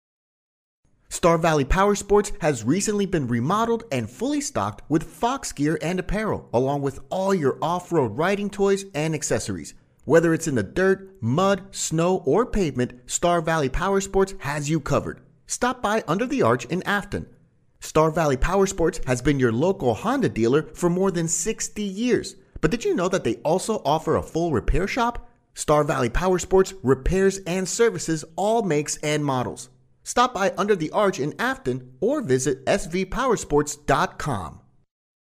美式英语配音【海豚配音】